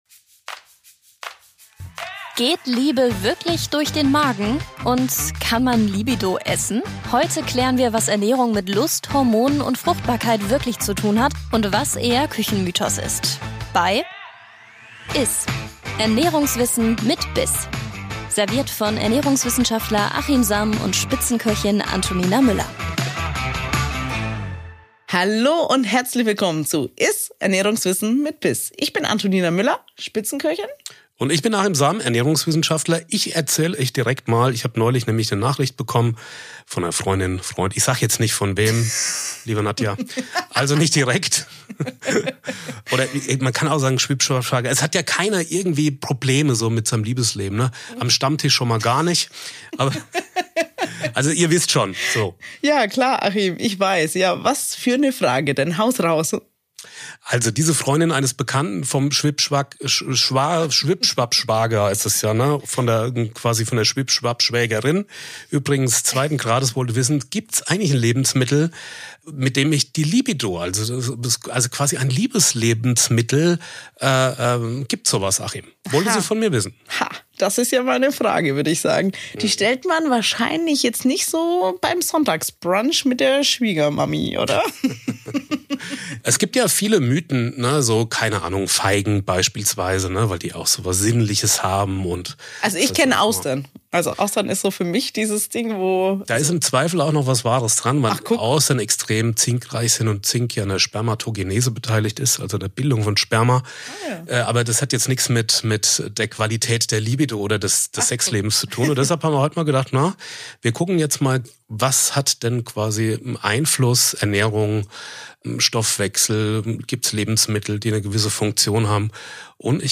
In dieser Folge sprechen Ernährungswissenschaftler